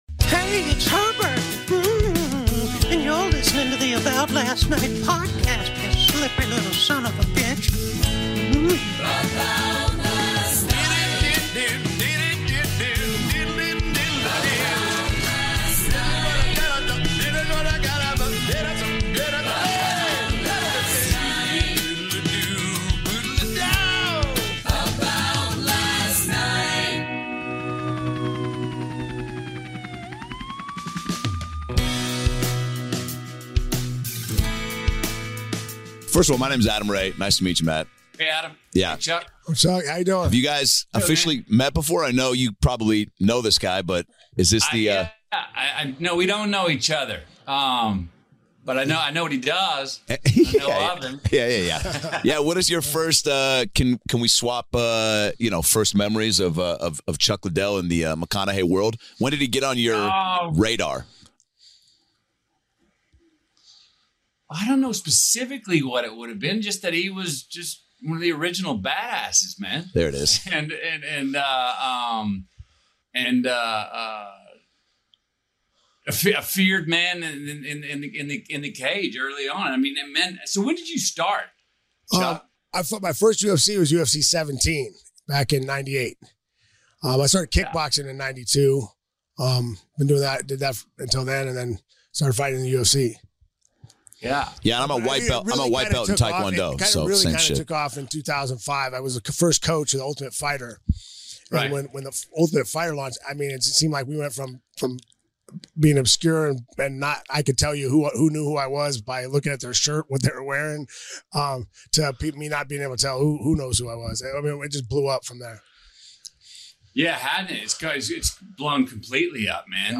In this special conversation